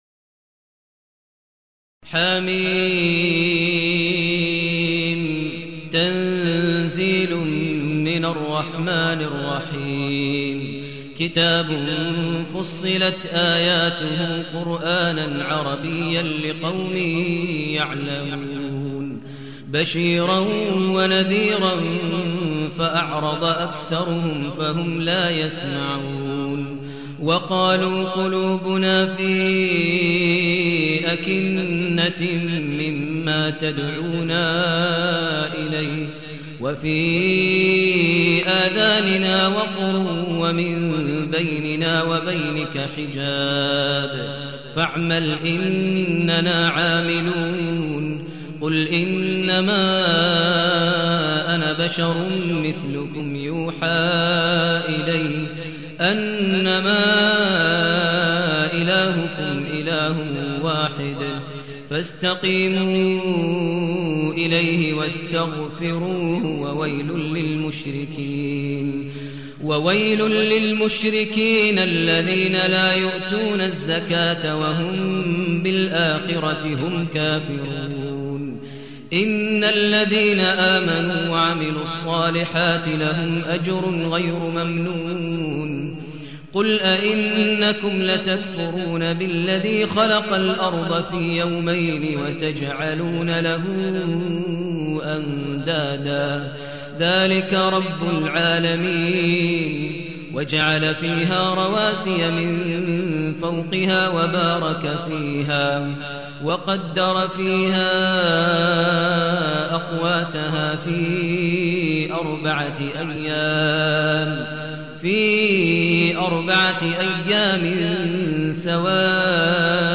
المكان: المسجد الحرام الشيخ: فضيلة الشيخ ماهر المعيقلي فضيلة الشيخ ماهر المعيقلي فصلت